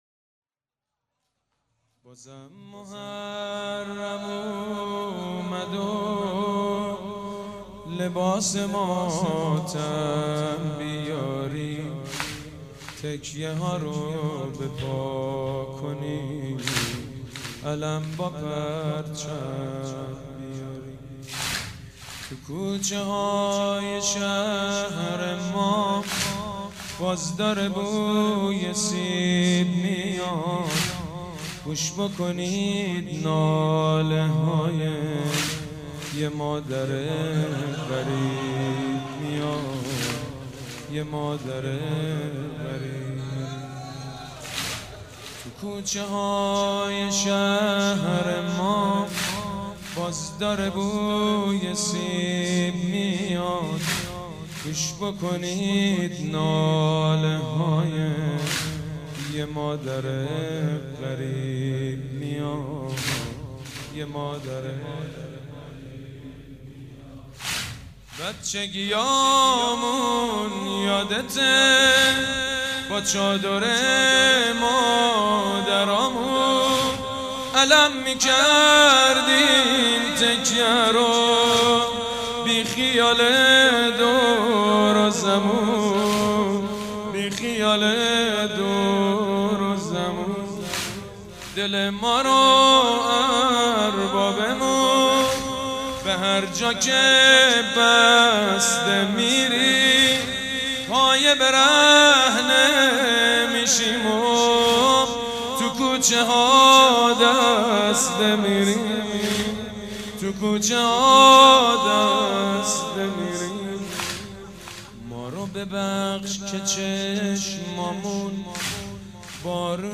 شب اول محرم - به نام نامی حضرت مسلم(ع)
سید مجید بنی فاطمه
واحد سید مجید بنی فاطمه